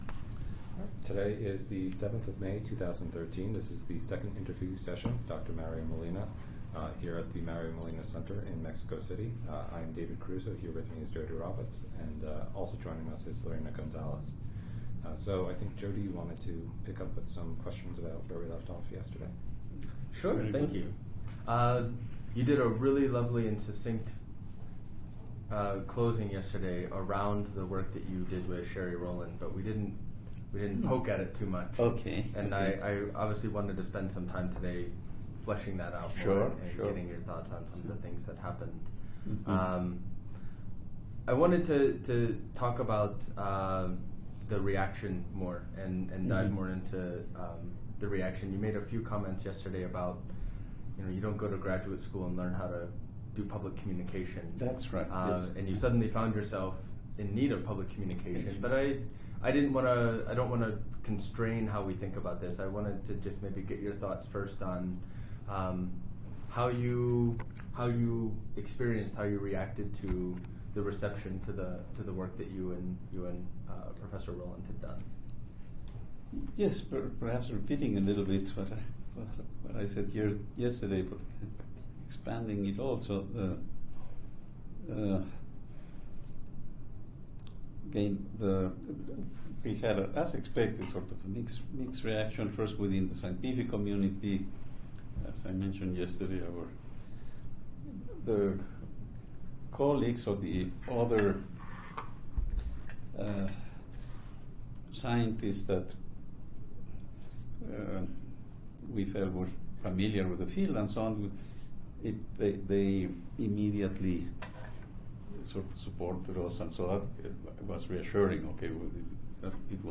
Oral history interview with Mario J. Molina